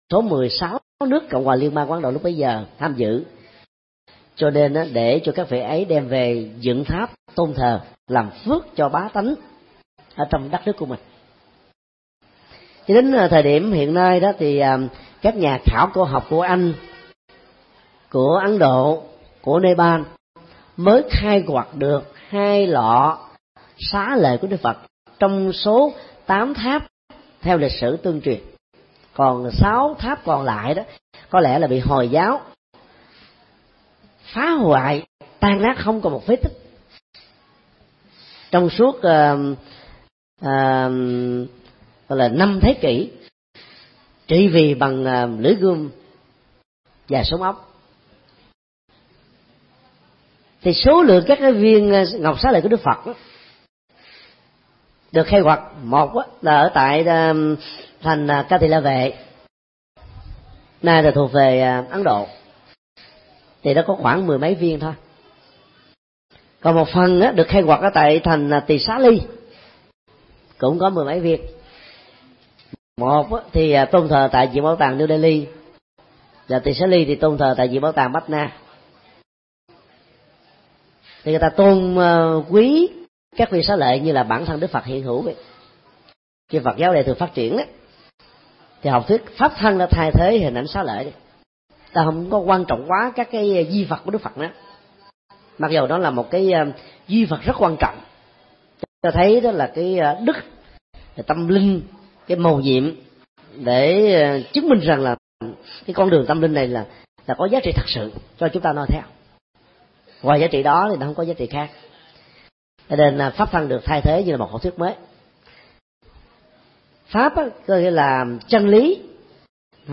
thuyết pháp
Mp3 Pháp âm Ý nghĩa quy y Tam Bảo
tại chùa Giác Ngộ